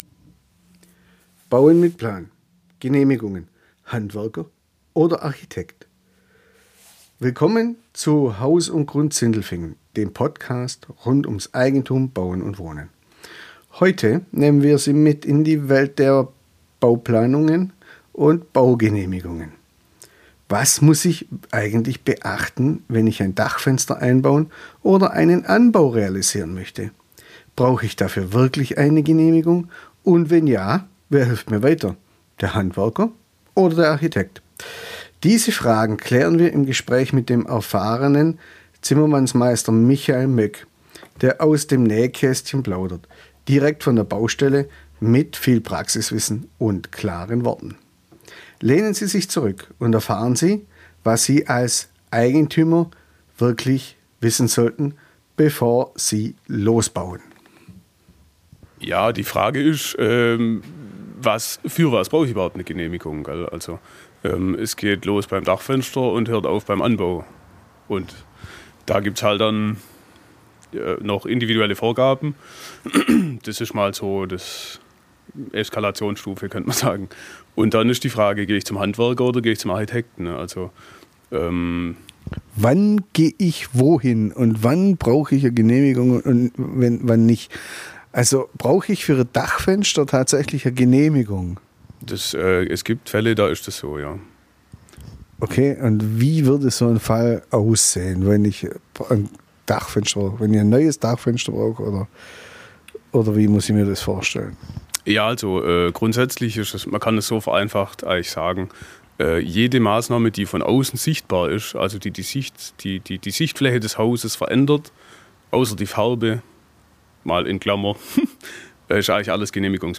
In dieser Episode von Haus & Grund Sindelfingen sprechen wir mit einem erfahrenen Zimmermeister über ein Thema, das viele Eigentümer beschäftigt: Was muss bei baulichen Veränderungen am Haus beachtet werden?